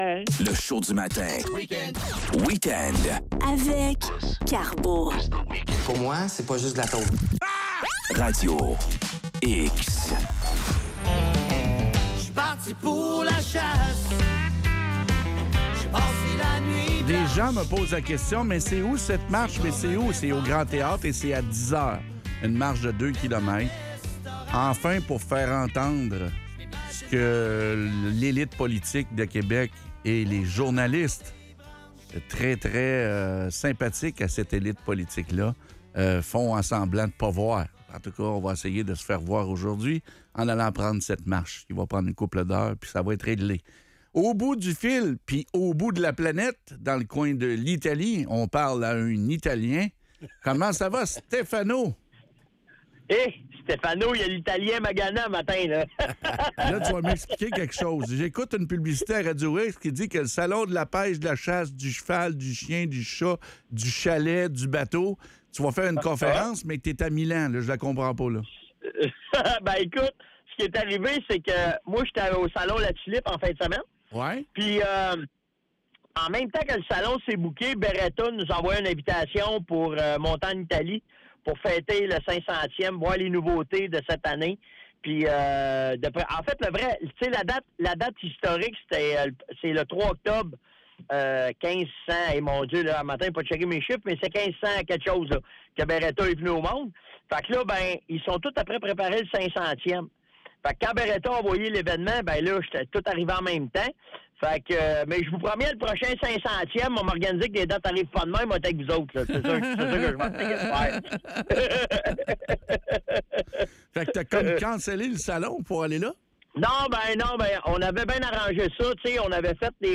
En direct de l’événement Beretta à Milan.